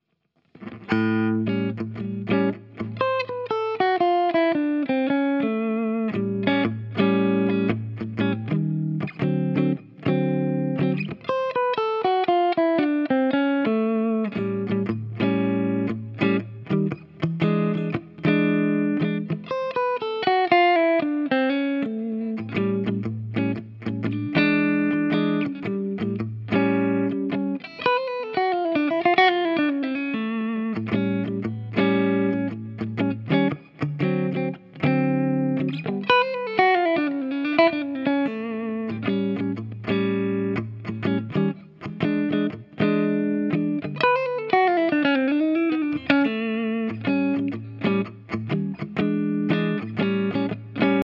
Guitar, Transcriptions, ,
Consider the case of “quick change” blues in A:
UPDATE: Here’s a recording of the first phrase used all three times in a 12-bar blues, and then the next three phrases used in turn the next time through: